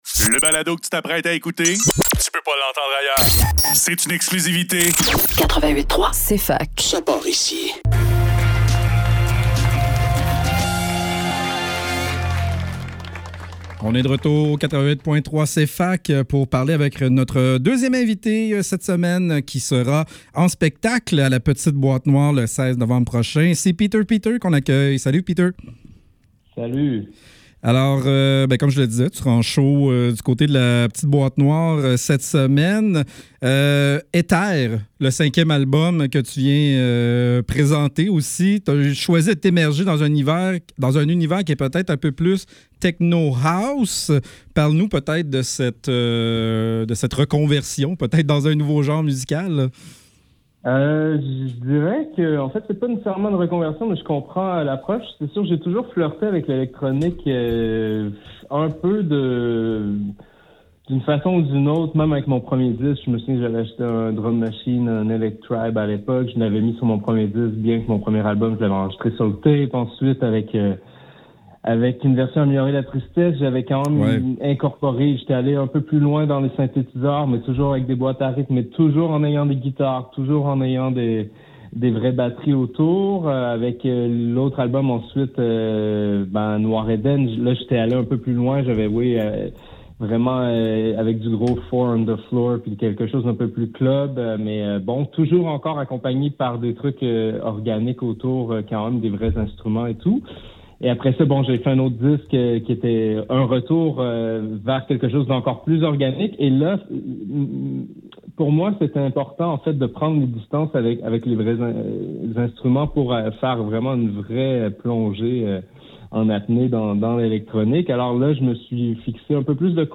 Faudrait que tout l'monde en parle - Entrevue avec Peter Peter - 13 novembre 2024